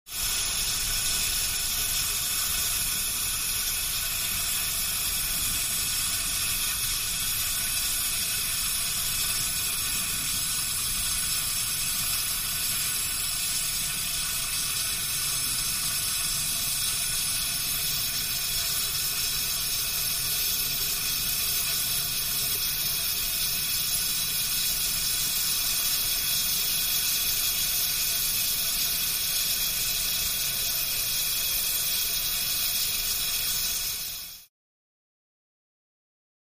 Oil Derrick; Steam Hiss Steady, Close Perspective